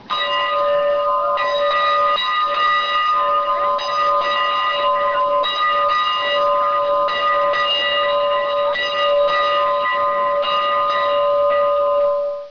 Click to hear the bell ring
CTELbell.wav